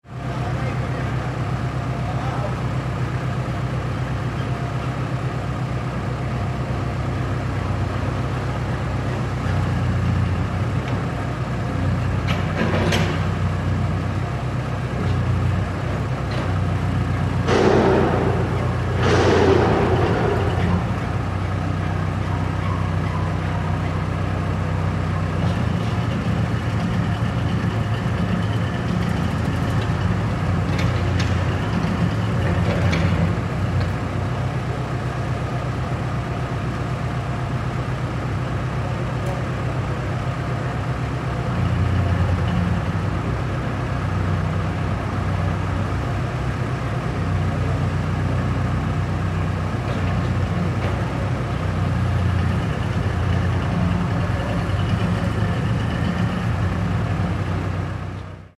Excavadora trabajando en un obra
maquinaria
ruido
Sonidos: Industria
Sonidos: Ciudad